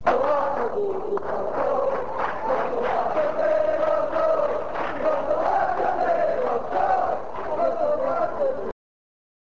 This page contains, for the moment, chants from the last game of the season at Easter Road on 8th May 1999, along with Alex McLeish's speech after the game.